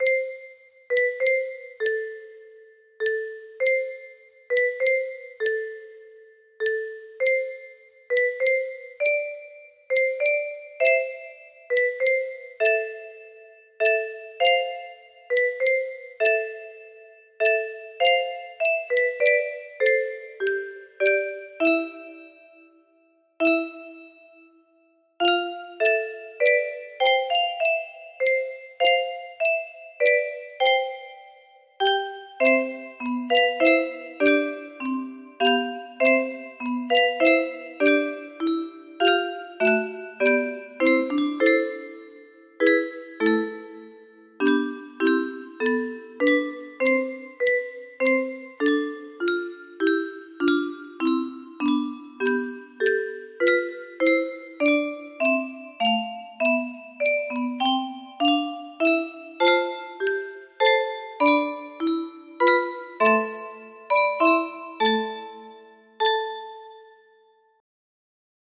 Illusion music box melody